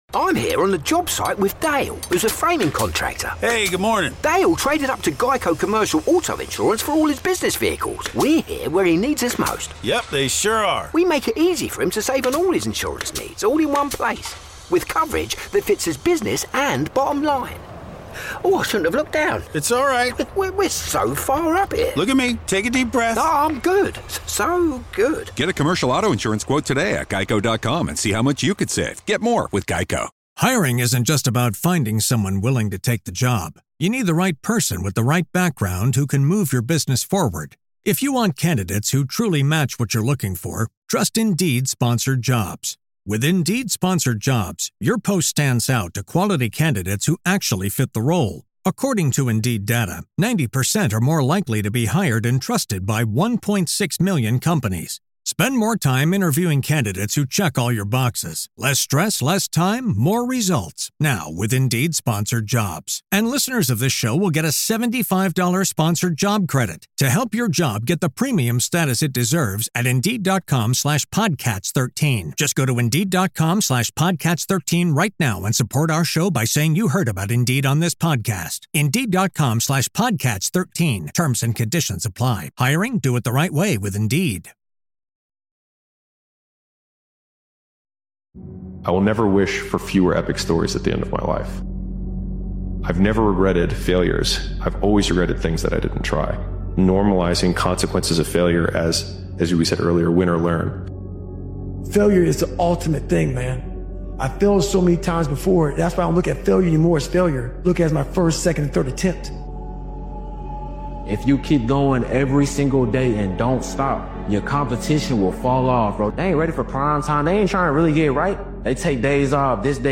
This powerful motivational speeches compilation is about unshakable determination—the mindset that refuses to accept defeat under any circumstance.